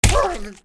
hell_dog4_get_hit2.ogg